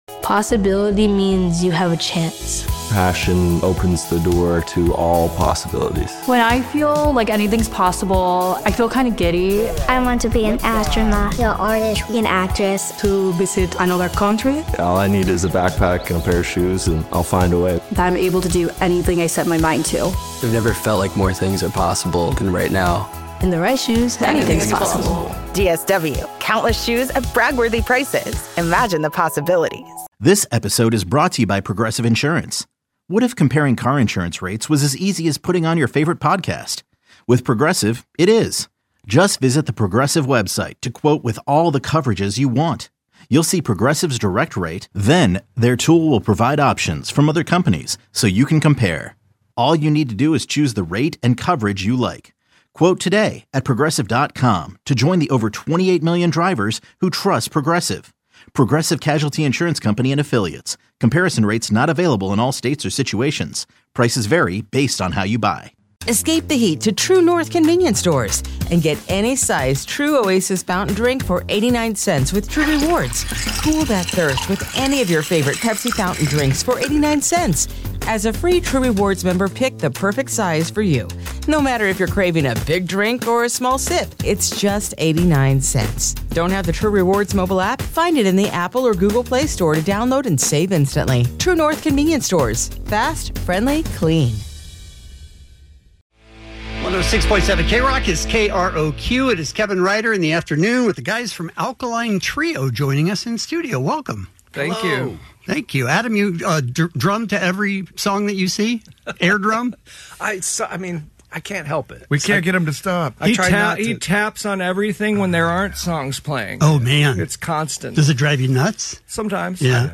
Alkaline Trio interview